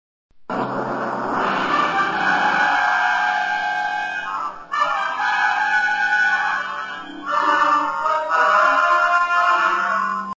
Mystery Sound